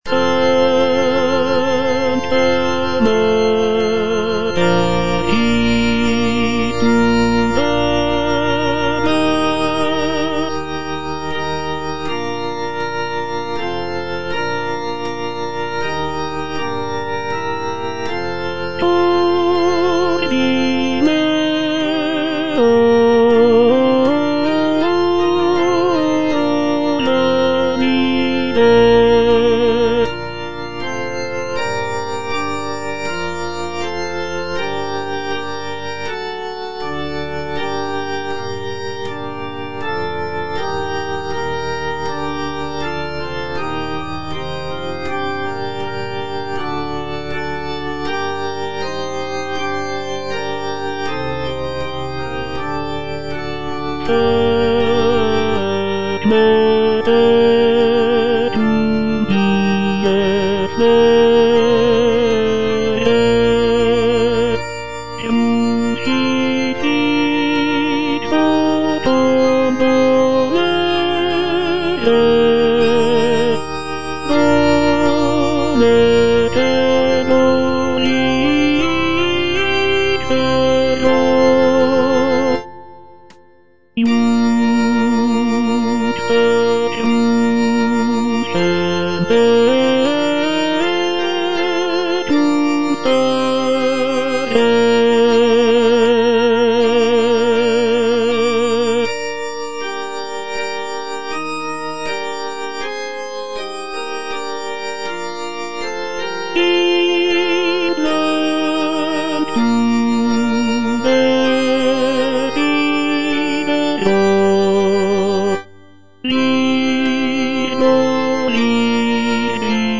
G.P. DA PALESTRINA - STABAT MATER Sancta Mater, istud agas (tenor II) (Voice with metronome) Ads stop: auto-stop
sacred choral work